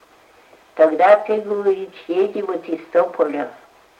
Оканье (полное оканье, свойственное Поморской группе севернорусского наречия – это различение гласных фонем /о/ и /а/ во всех безударных слогах)